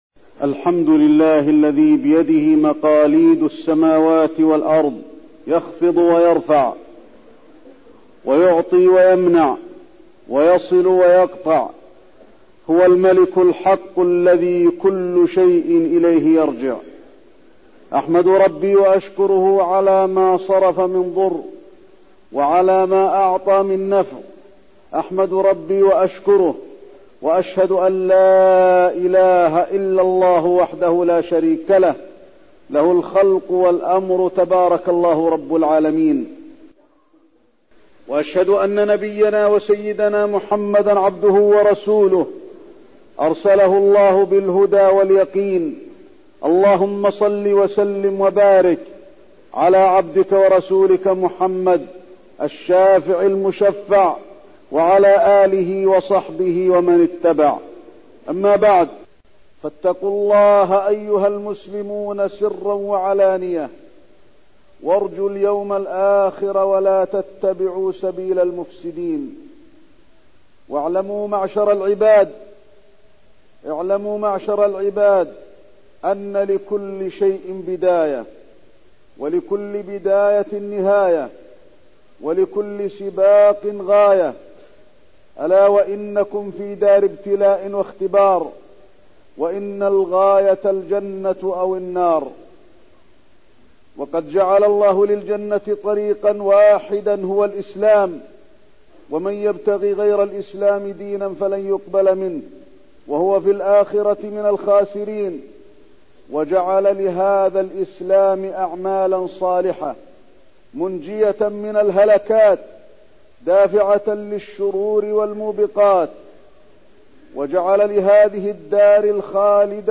تاريخ النشر ٣ جمادى الآخرة ١٤١٣ هـ المكان: المسجد النبوي الشيخ: فضيلة الشيخ د. علي بن عبدالرحمن الحذيفي فضيلة الشيخ د. علي بن عبدالرحمن الحذيفي فتنة الشيطان